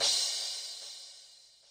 -0db_weed dem_808crash---0db_2.wav